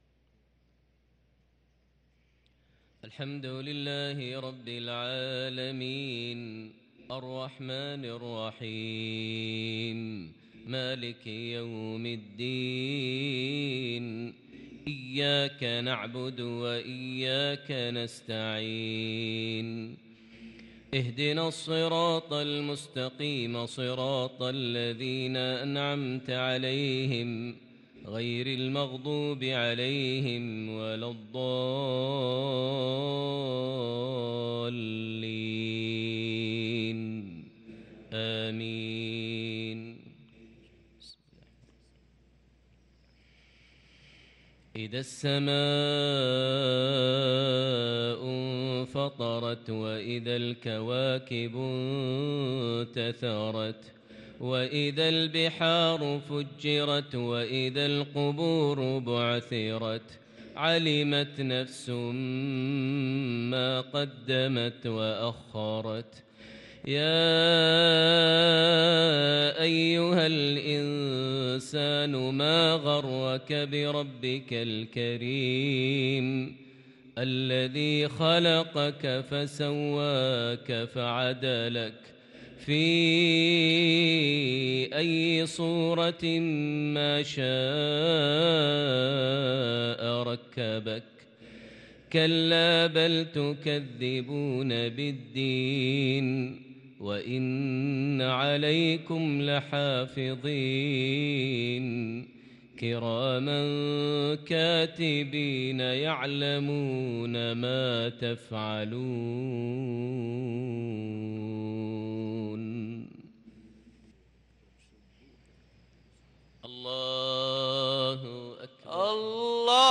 صلاة المغرب للقارئ ماهر المعيقلي 7 شوال 1443 هـ
تِلَاوَات الْحَرَمَيْن .